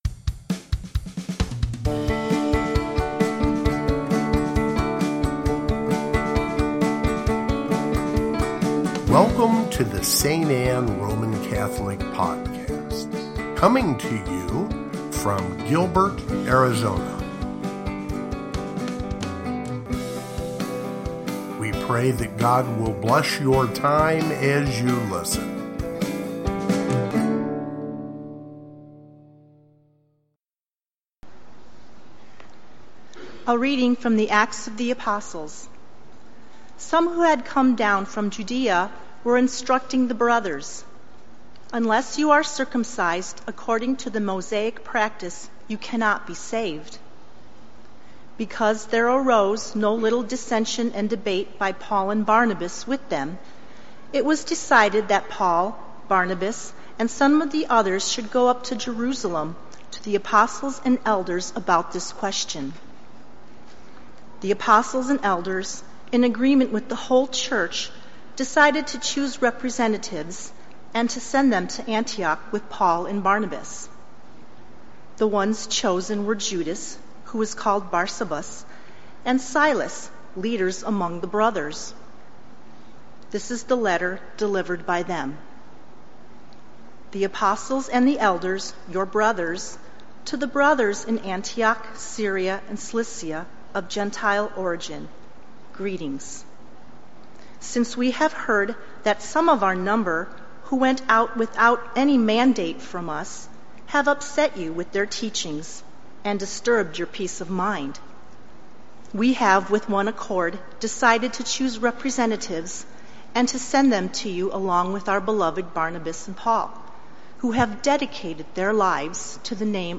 Sixth Sunday of Easter (Readings)
Gospel, Readings, Easter